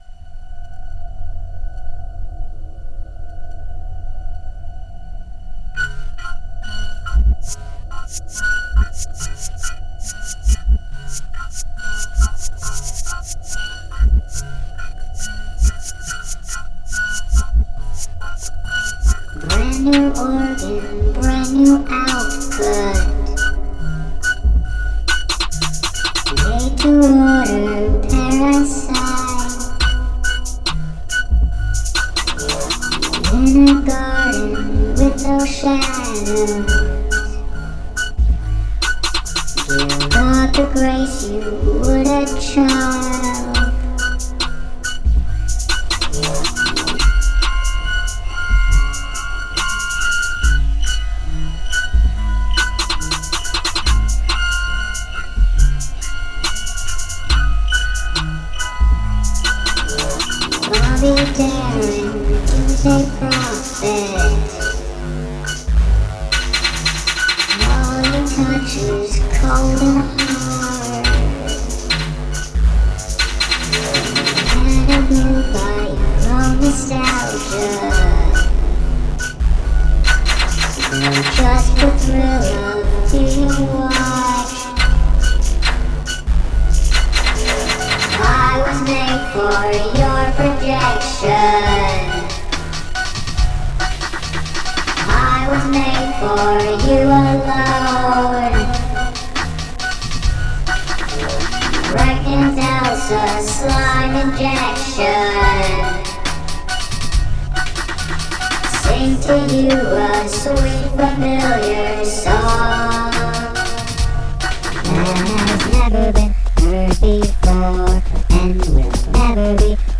I finished a nightcore version of the song I wrote on my neighbor's omnichord in December:
Pregnant_Elsa_Slime_Injection_Demo_2026.mp3